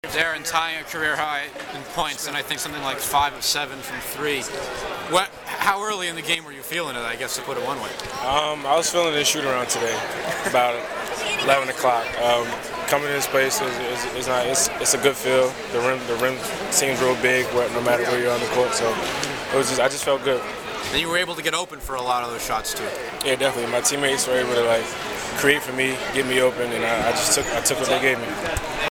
Postgame audio